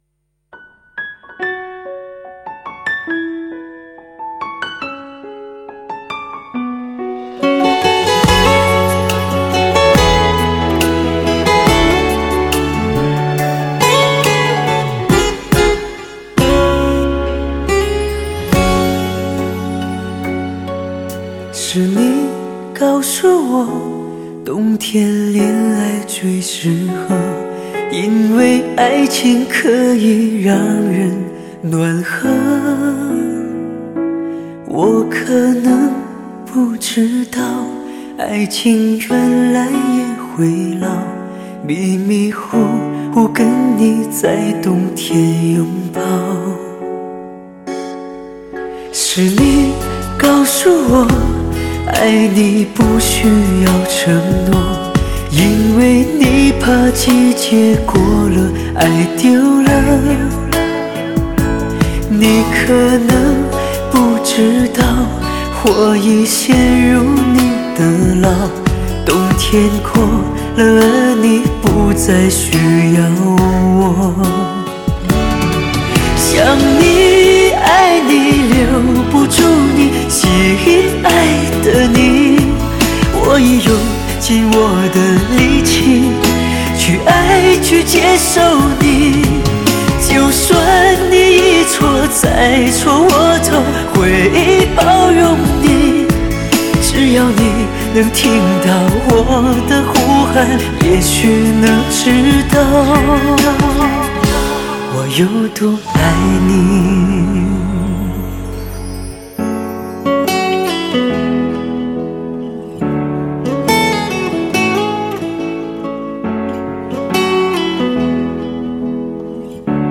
现场模拟录音
最具有感染力的沙哑情感男声